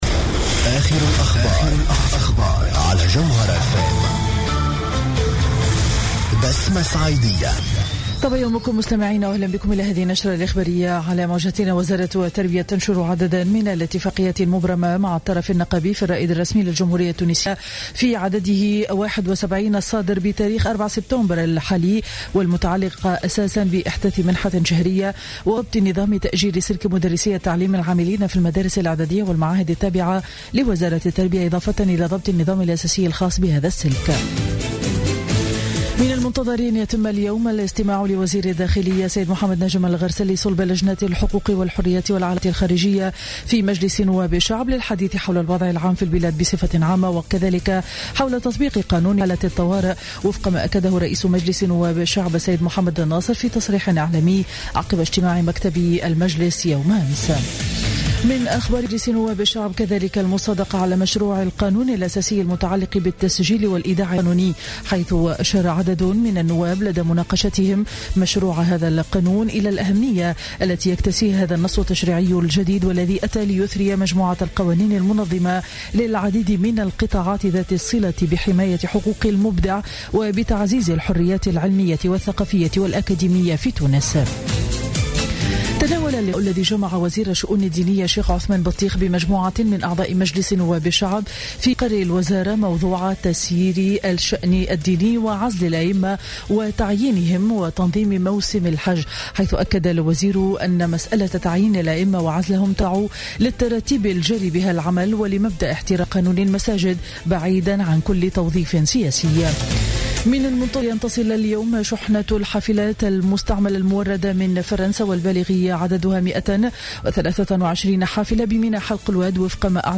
نشرة أخبار السابعة صباحا ليوم الخميس 10 سبتمبر 2015